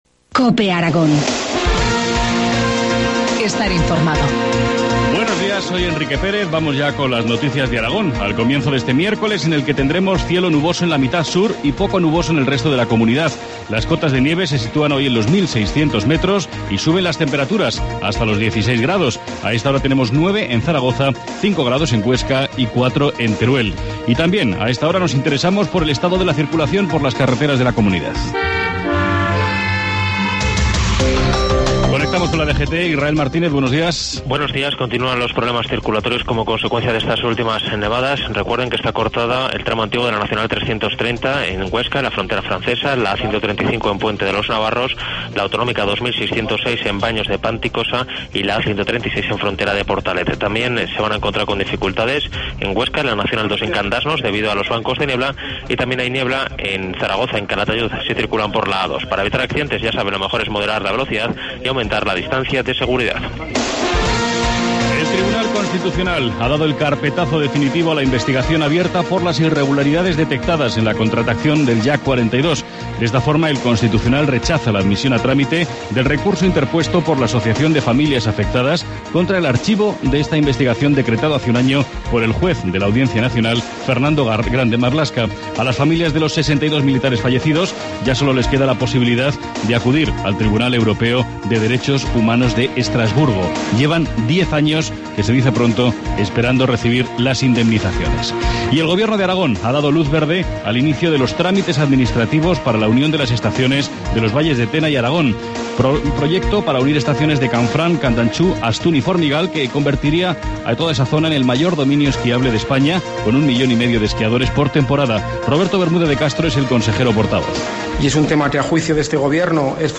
Informativo matinal, miércoles 20 de febrero, 7.25 horas